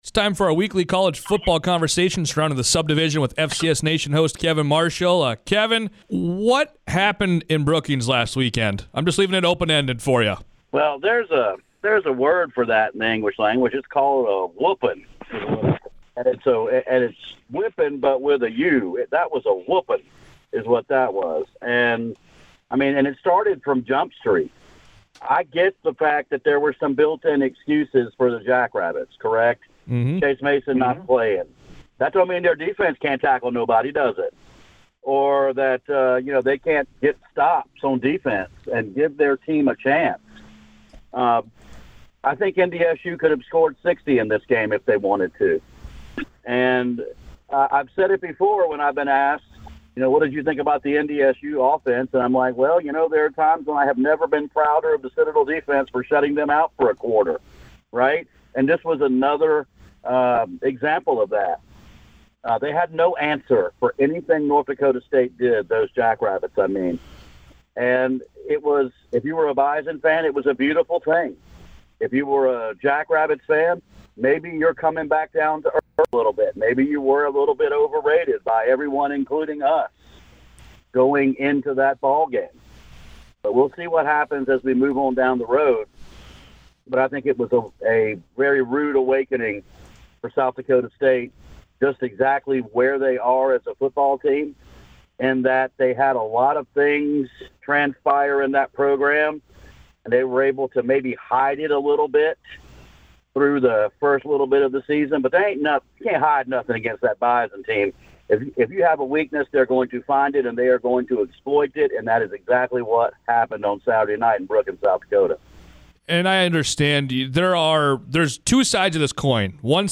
weekly college football conversation. They recapped the Dakota Markers game, preview this week’s top games, and more.